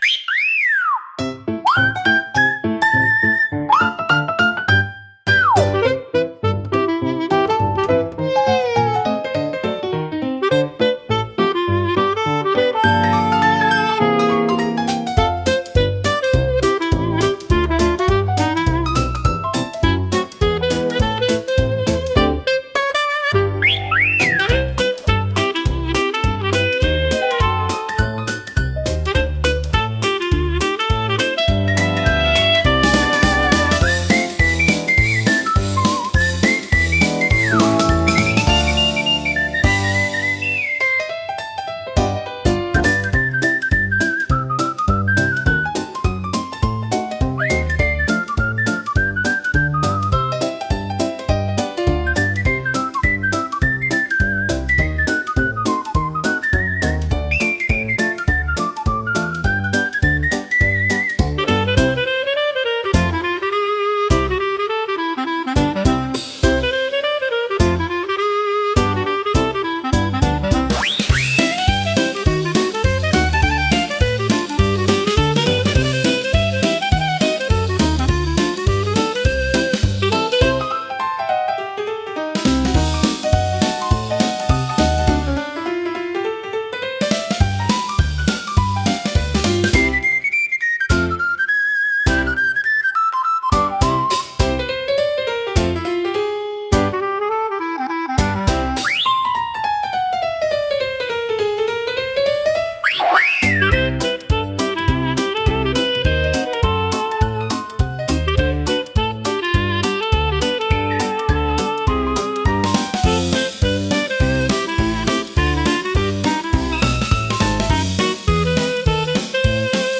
軽快なリズムと遊び心あふれるメロディが、聴く人に楽しさと自由なエネルギーを届けます。
口笛を主体にした明るく活発な楽曲で、元気いっぱいの陽気な雰囲気が特徴です。
気軽で楽しい空気を作り出し、場を明るく盛り上げるジャンルです。